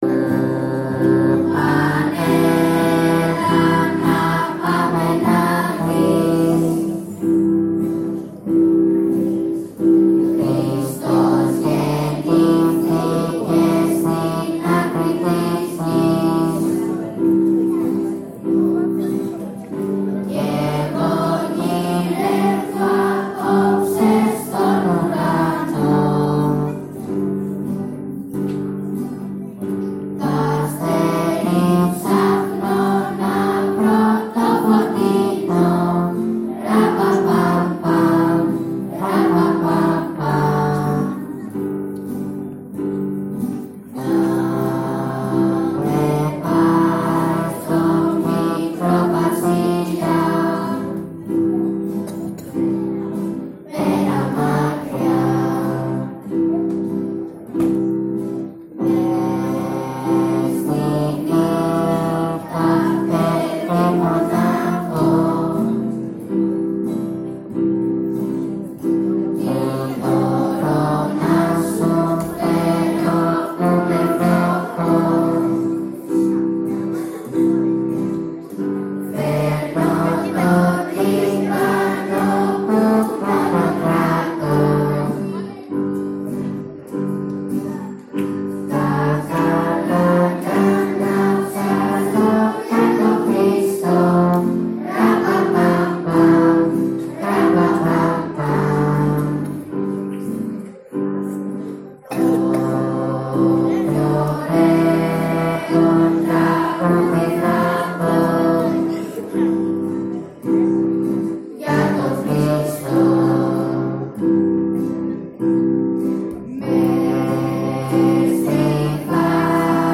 Την Πέμπτη 20 Δεκεμβρίου, πραγματοποιήθηκε η χριστουγεννιάτικη γιορτή του σχολείου μας στην Ενοριακή Αίθουσα Βάρης.
Τα παιδιά της Α΄, της Β΄ και της Γ΄τάξης παρουσίασαν τα θεατρικά τους με κέφι και ζωντάνια, ενώ οι μαθητές της Ε΄και ΣΤ΄ τάξης έντυσαν τη γιορτή με όμορφα τραγούδια.